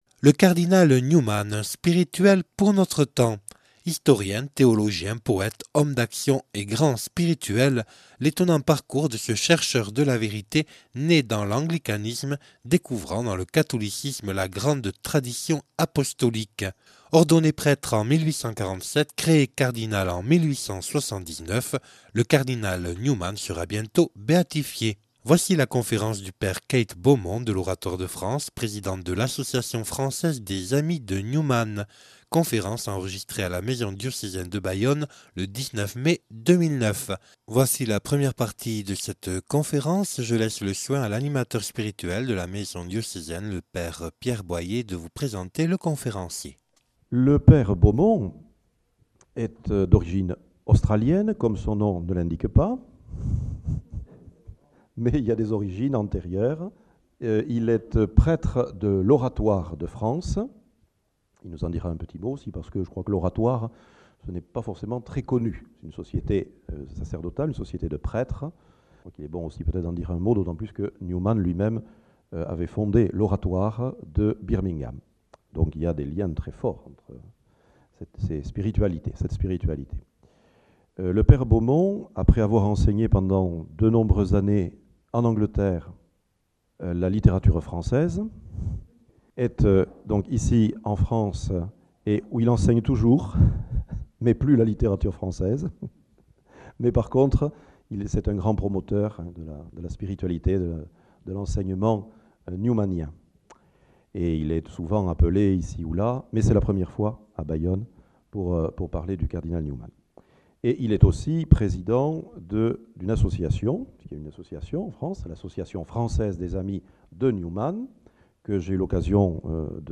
(Enregistrée le 19/05/2009 à la maison diocésaine de Bayonne).